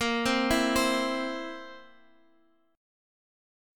Bbsus2b5 Chord